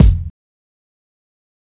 SouthSide Kick (10).wav